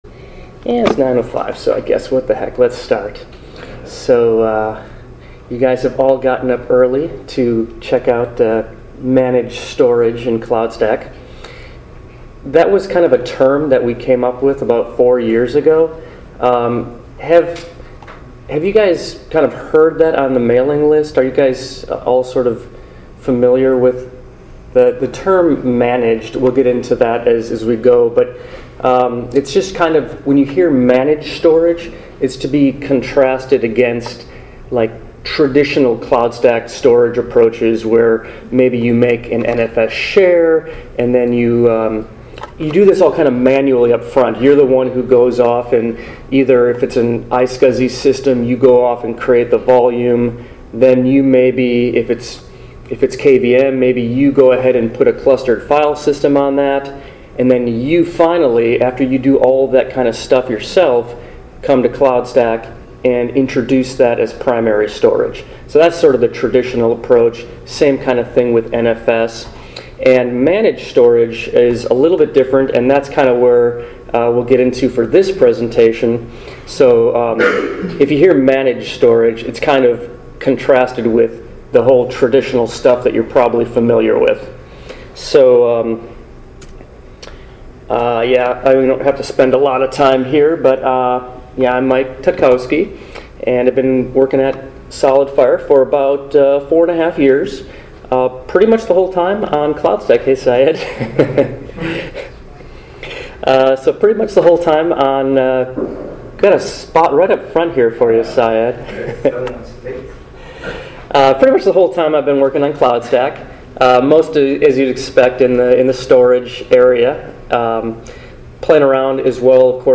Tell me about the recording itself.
ApacheCon Miami 2017 – What is Managed Storage and Why is it Important? SolidFire Cloudstack Collaboration Conference